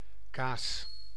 Ääntäminen
Ääntäminen : IPA: /kaːs/ Tuntematon aksentti: IPA: /ka:s/ Haettu sana löytyi näillä lähdekielillä: hollanti Käännös Konteksti Ääninäyte Substantiivit 1. fromage {m} ruoanlaitto France (Paris) Suku: m .